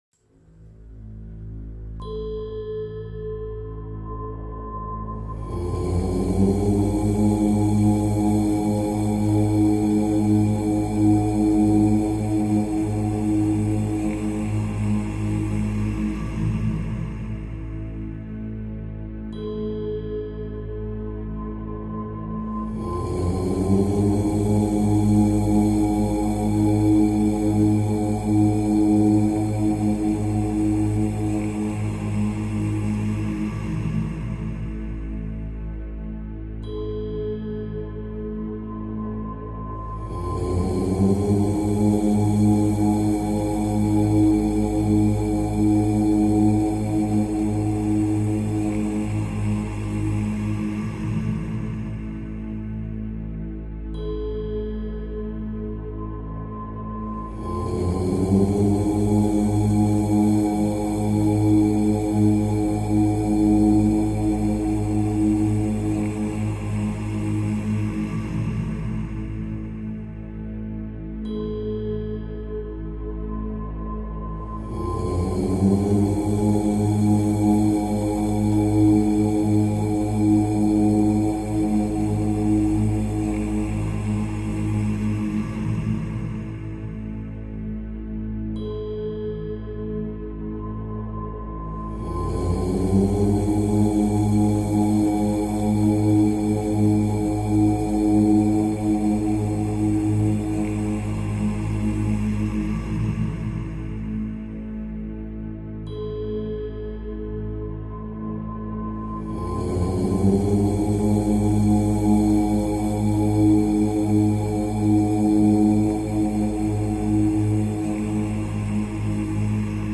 Om-MP3.m4a